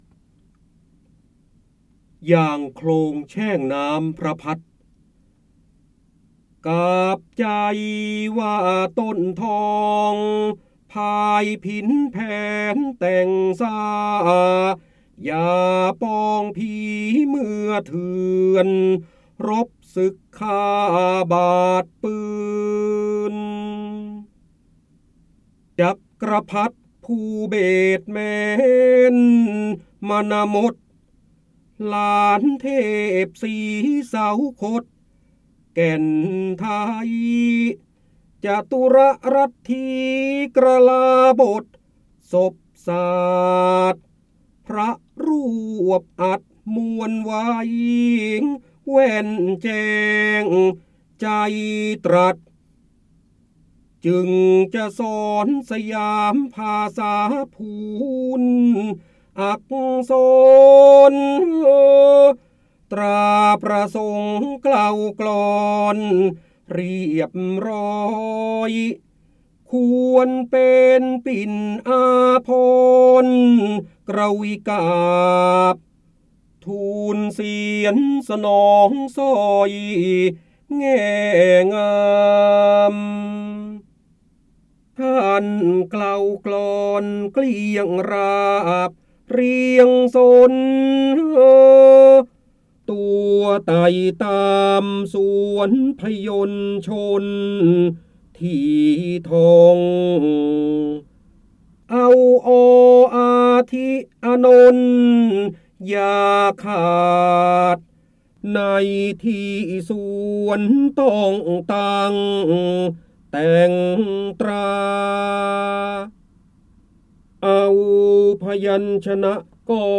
เสียงบรรยายจากหนังสือ จินดามณี (พระโหราธิบดี) อย่างโคลงแช่งน้ำพระพัฒน์
ลักษณะของสื่อ :   คลิปเสียง, คลิปการเรียนรู้